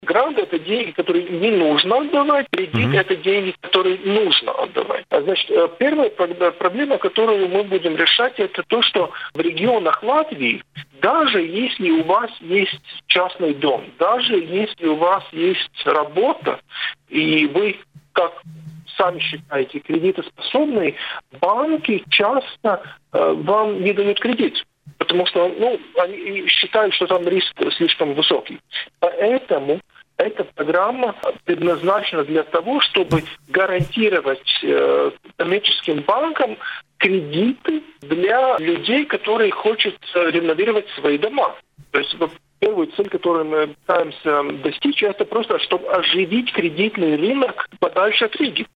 Об этом в эфире радио Baltkom